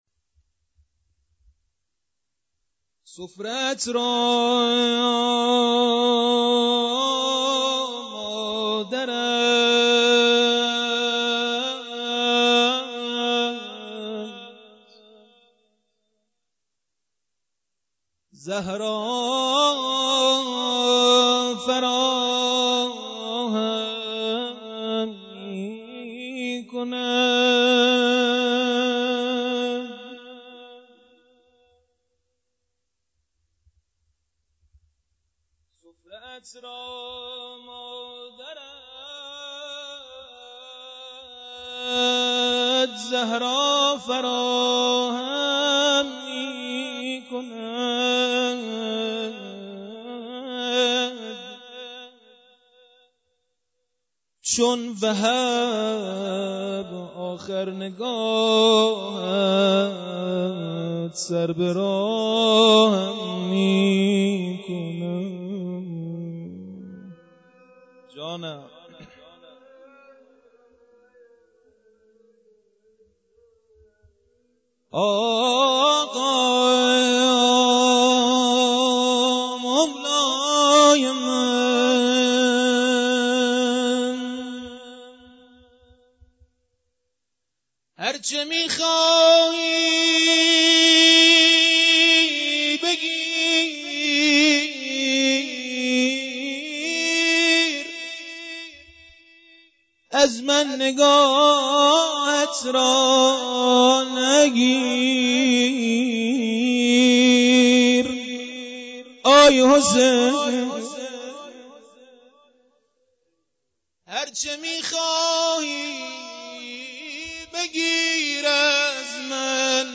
مداحی
محرم سال1397